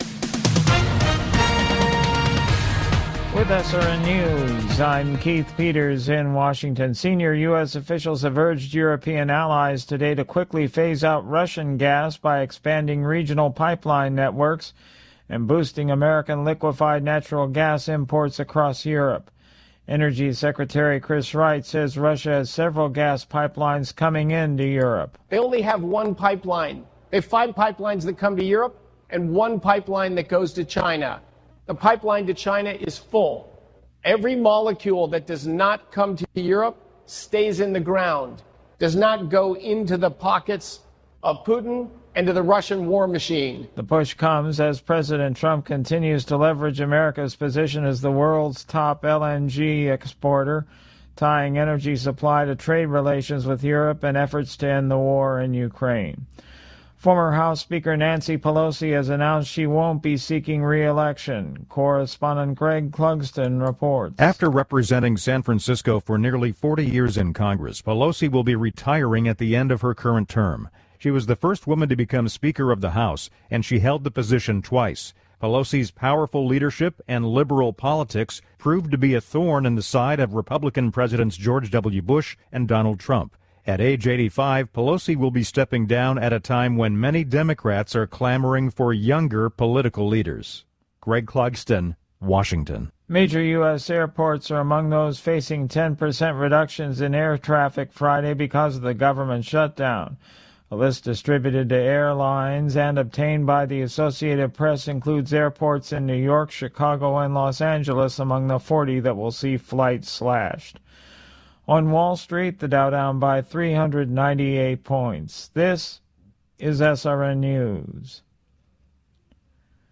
Top News Stories Nov 06, 2025 – 08:00 PM CST